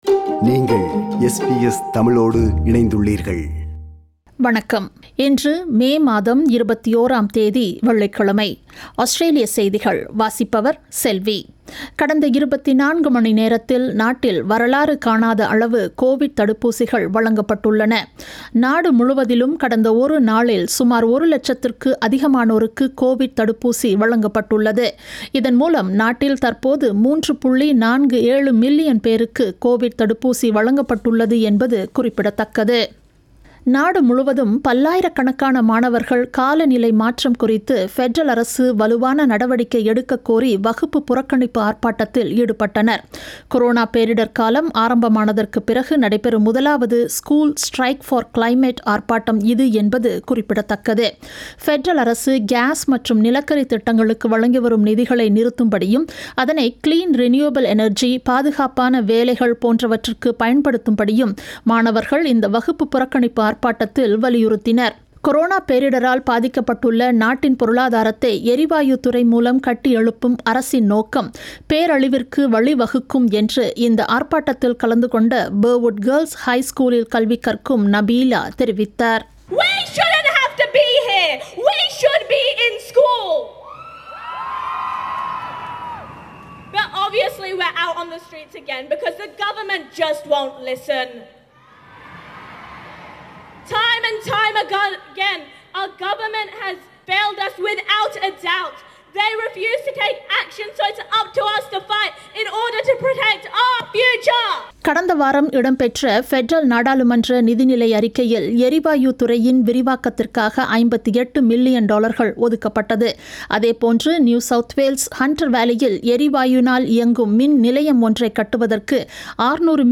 ஆஸ்திரேலிய செய்திகள்